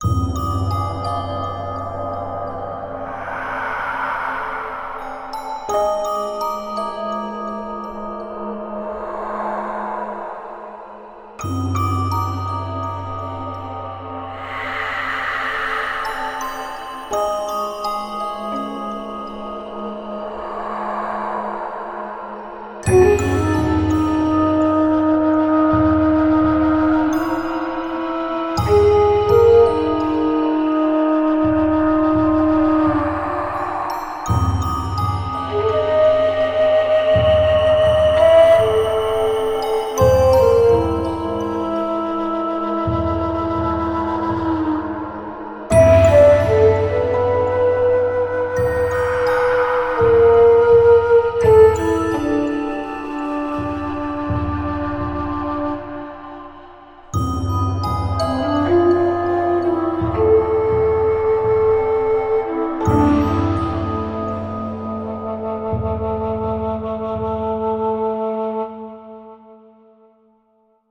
生与死，绝望与希望，漫漫黄泉路凄凄排箫声，人鬼江湖忘川路